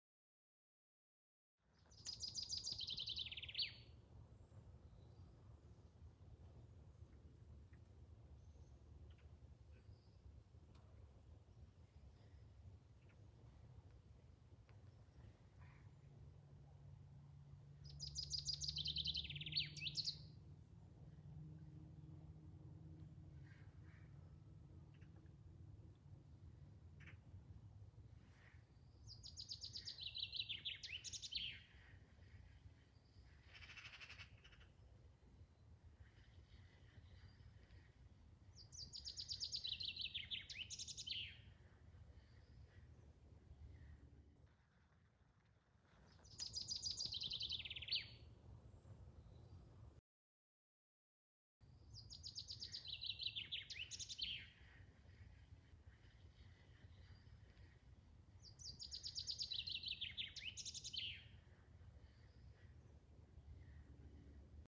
Пение и другие звуки птицы зяблика слушать онлайн.
9. Зяблик красиво поет утром во дворе на дереве
ziablik-utrom.mp3